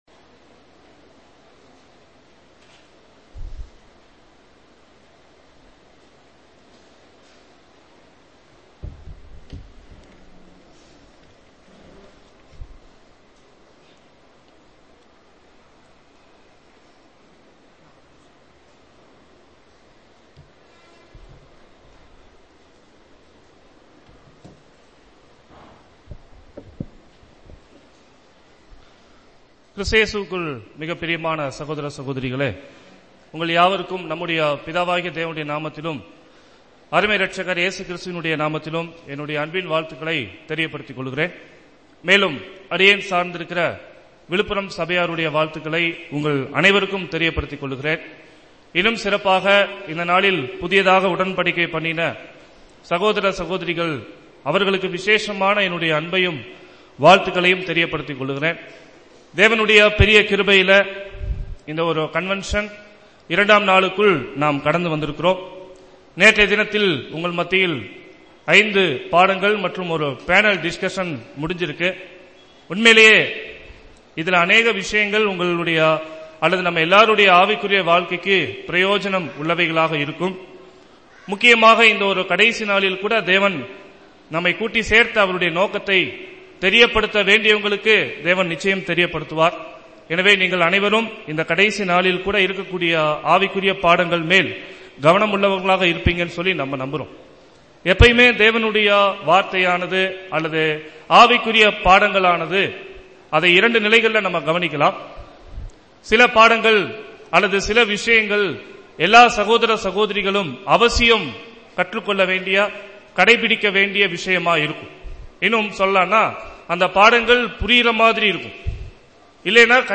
Index of /Tamil_Sermons/2012_DINDUGAL_CONVENTION_MAY